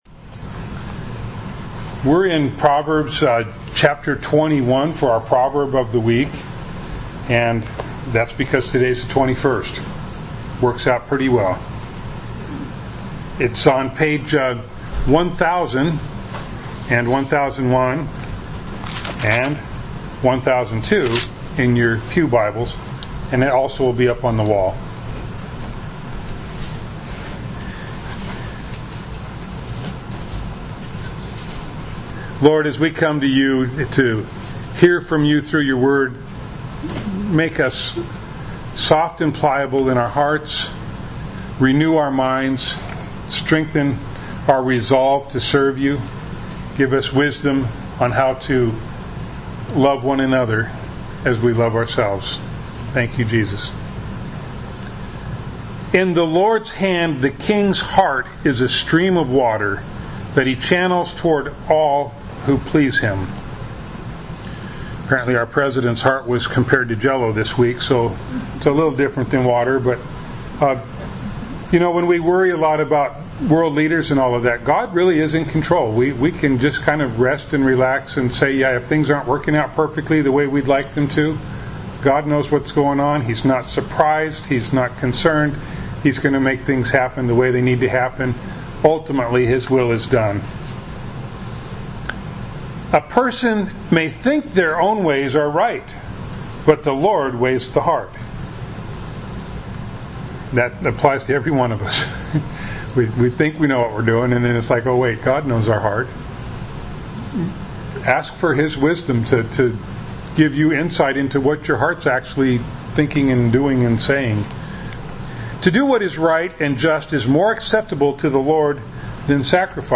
James 2:19 Service Type: Sunday Morning Bible Text